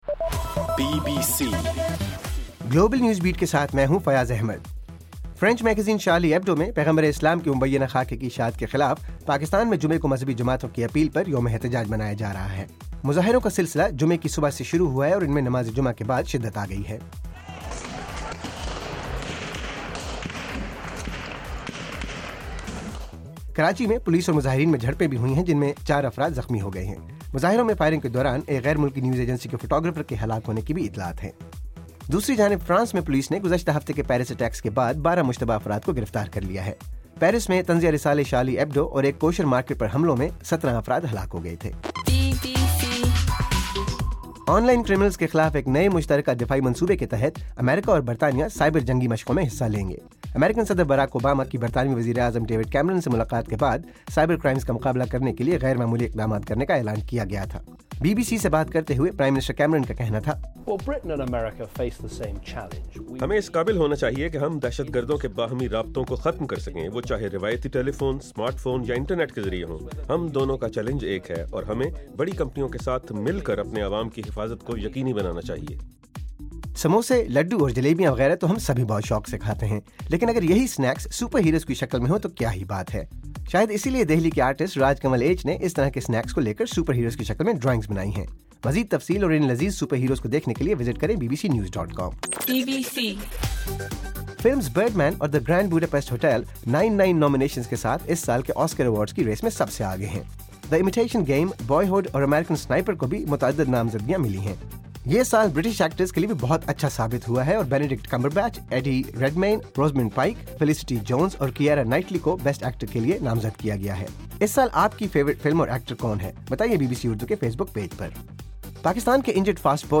جنوری 16: رات 8 بجے کا گلوبل نیوز بیٹ بُلیٹن